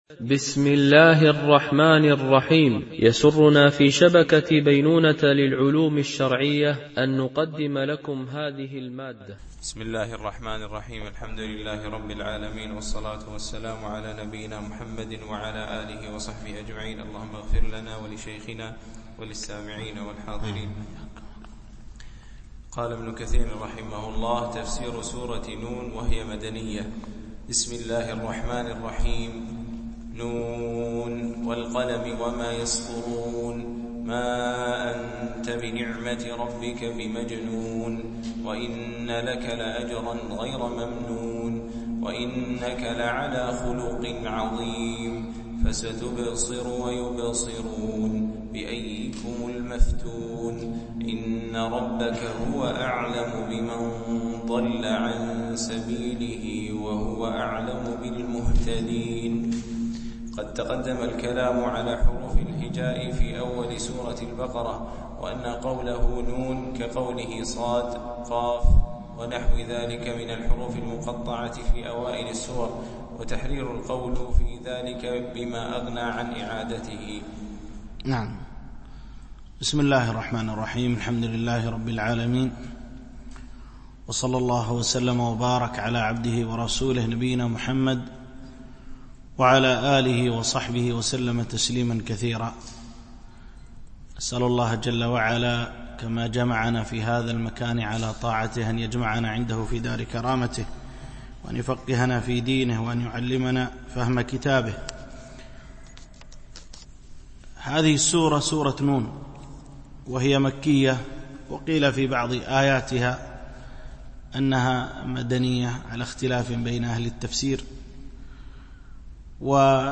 شرح مختصر تفسير ابن كثير(عمدة التفسير) الدرس 64 (سورة القلم)
MP3 Mono 22kHz 32Kbps (CBR)